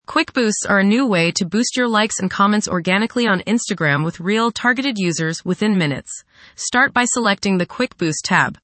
audio%2Fsynthetic-voice%2F5665e179-e97f-4b48-b30b-757d3c33a89b.mp3